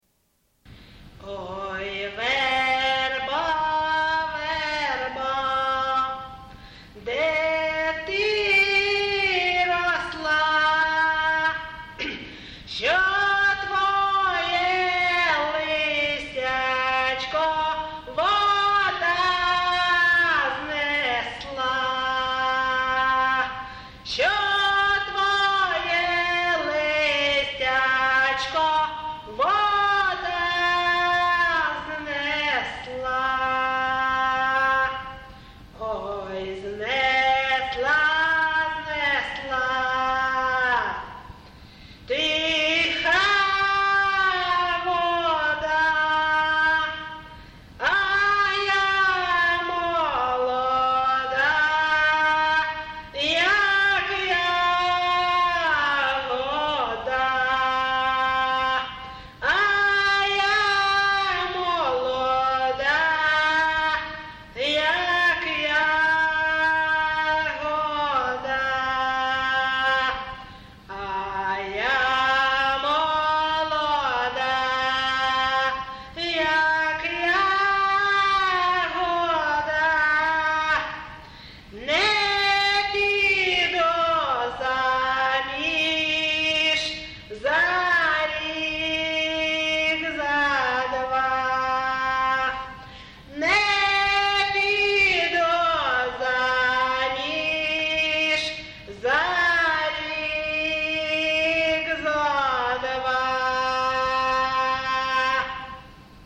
ЖанрПісні з особистого та родинного життя
МотивЖурба, туга
Місце записус. Ковалівка, Миргородський район, Полтавська обл., Україна, Полтавщина